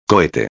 [co·he·te]